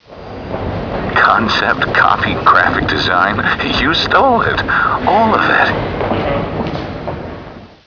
THE LONDON SUN & NEWS OF THE WORLD's confidential sources have discovered that a series of threatening and possibly revealing telephone calls are being received from a source or sources unknown who apparently have information on the whereabouts of Meg Townsend.
Written transcripts of audio clues (TXT files) are available for users without sound-cards -- but where possible, we recommend downloading the sound files, because the background sounds and audio subtleties can be helpful in formulating your theory!